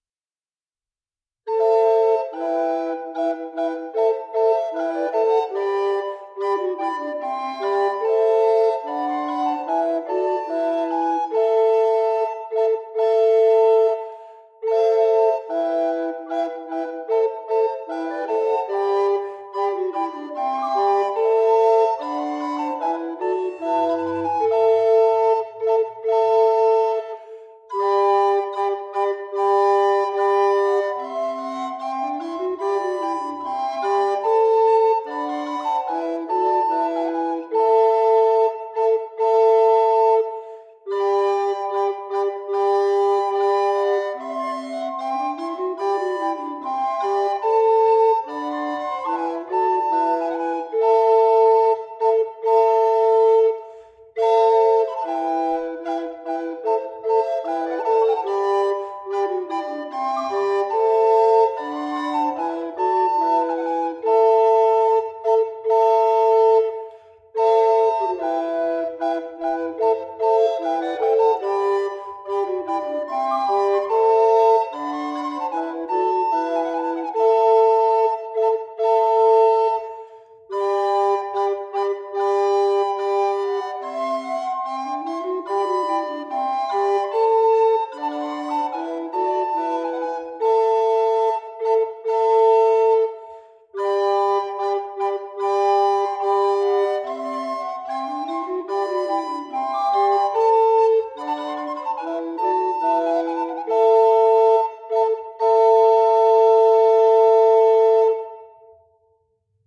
Ministriles coloniales de Guatemala
Música tradicional